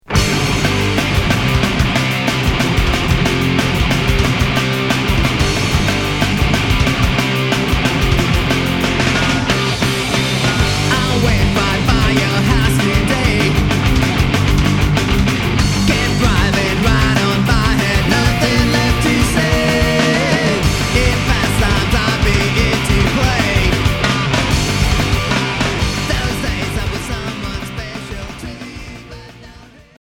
Punk hardcore